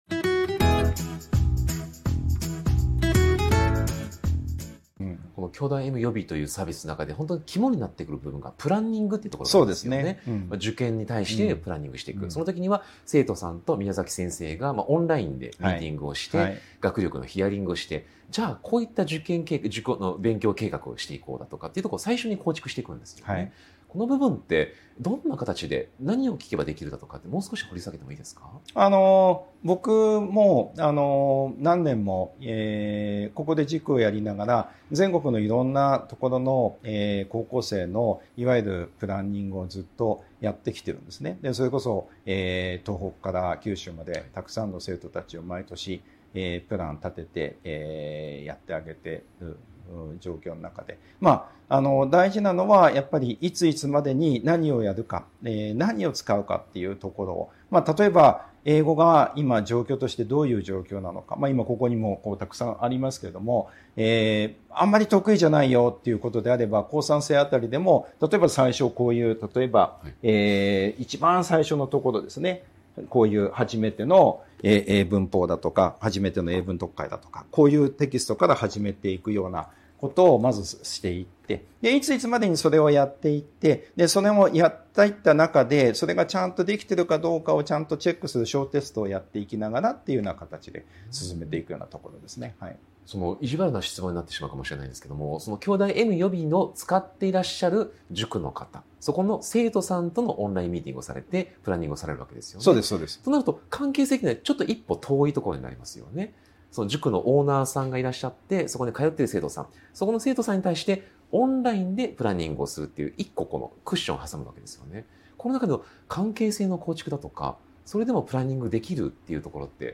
【特別インタビュー】塾業界38年の現場から見える未来の学習塾経営とは 個人塾が生き残るための「縦展開」と「寄り